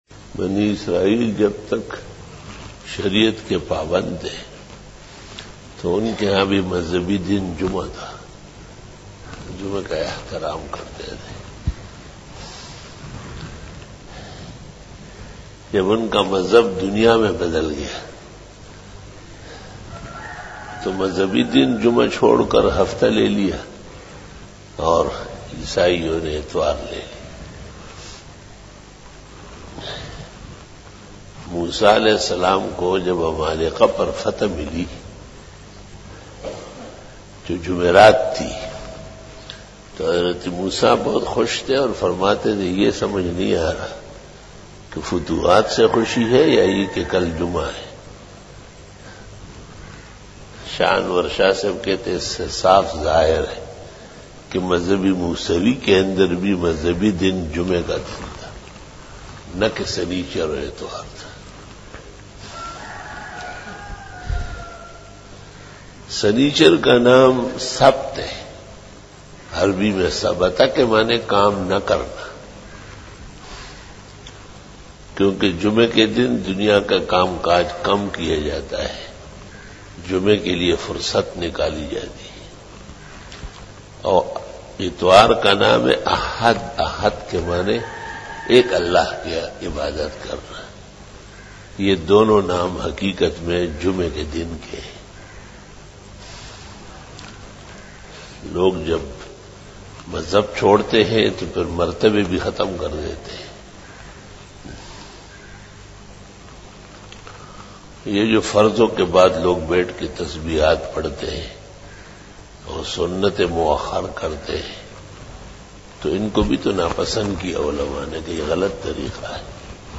After Namaz Bayan
بیان بعد نماز فجر